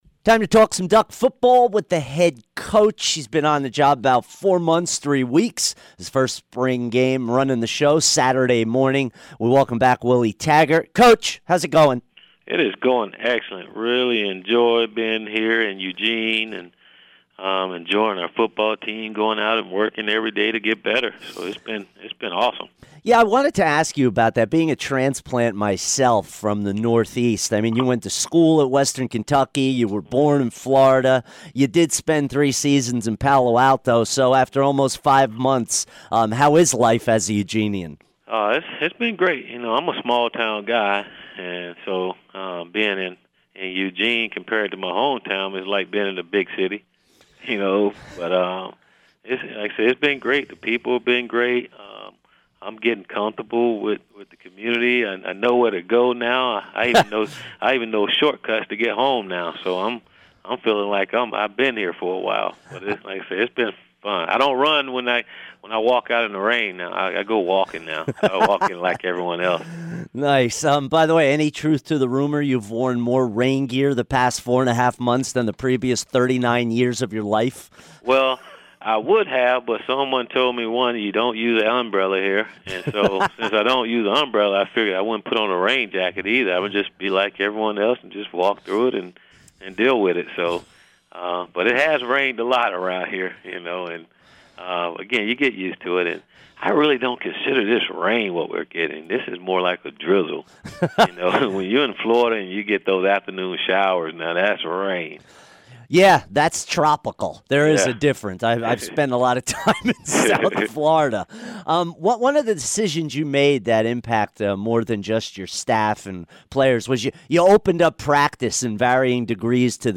Willie Taggart Interview 4-27-17